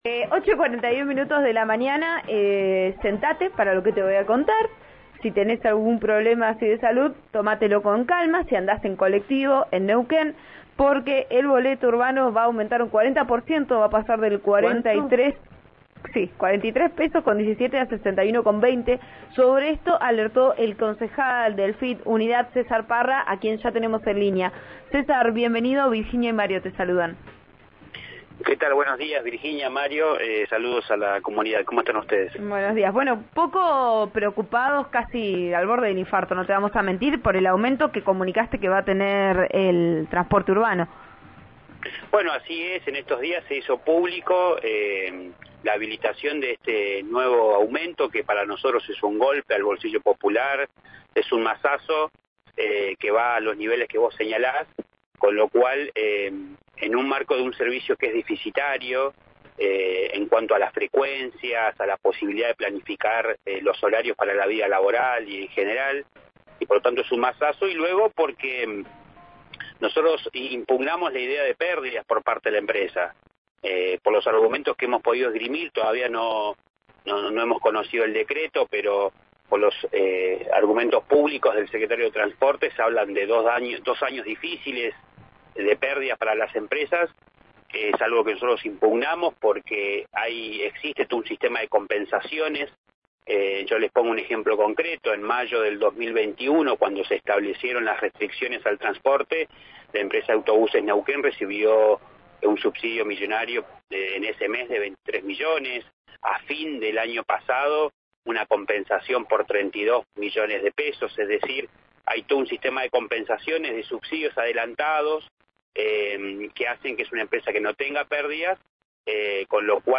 El lunes, en diálogo con «Vos A Diario» (RN RADIO 89.3) agregó que estaba organizando una junta de firmas para esta semana.